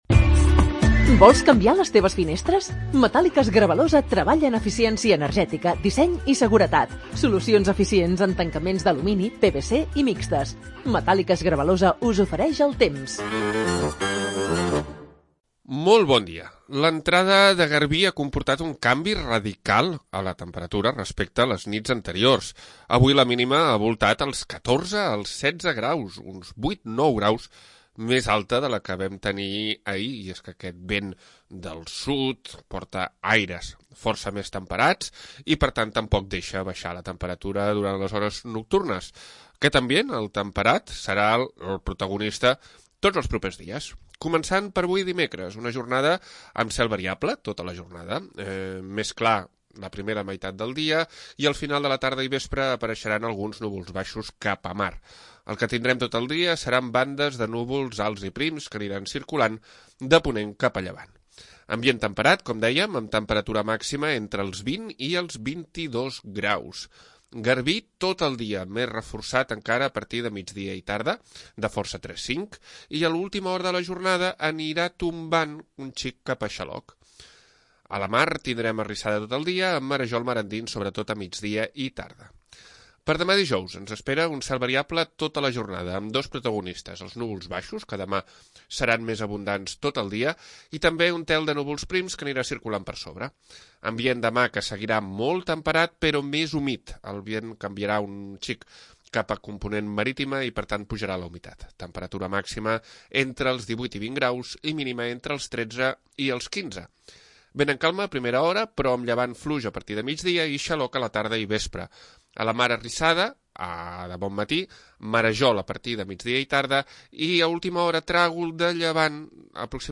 Previsió meteorològica 12 de novembre de 2025